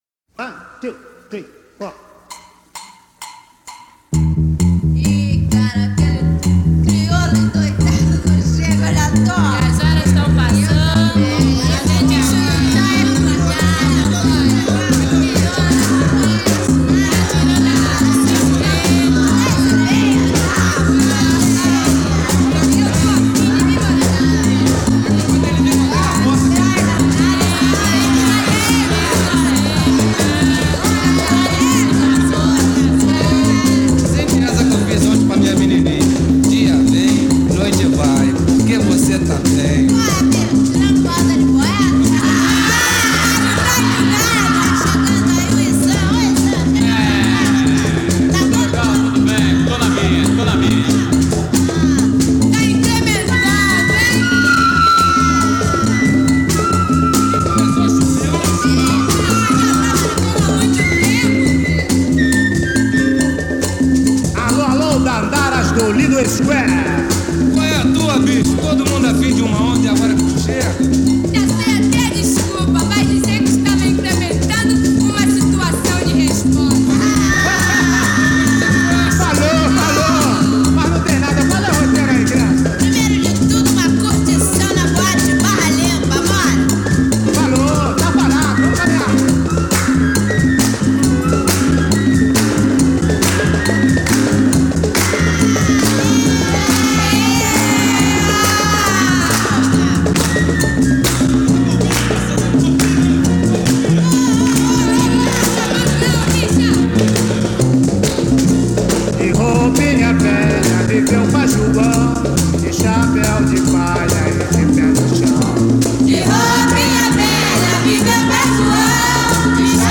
圧倒的なフリーク・アウト感を漂わせる、ブラジル産アフロ・サイケの最高峰！